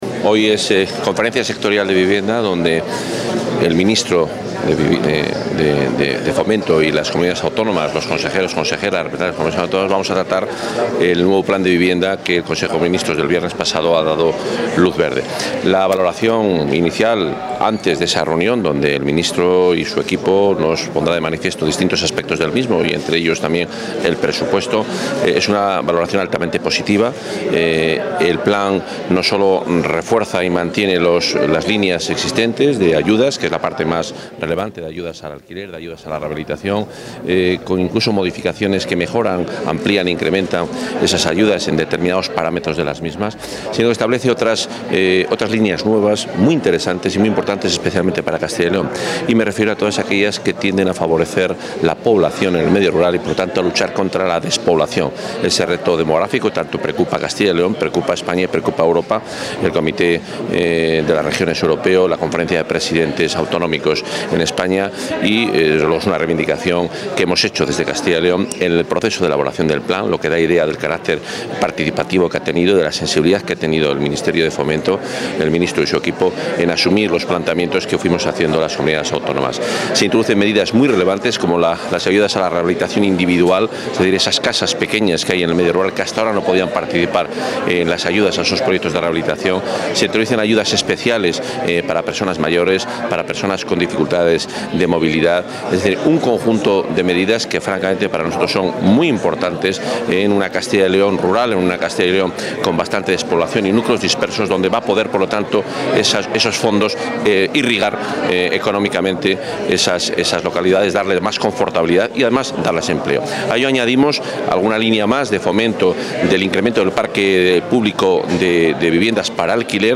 Intervención de Juan Carlos Suárez-Quiñones.
El consejero de Fomento y Medio Ambiente, Juan Carlos Suárez-Quiñones, asiste hoy a la Conferencia Sectorial de Vivienda, Urbanismo y Suelo, que convoca el Ministerio de Fomento.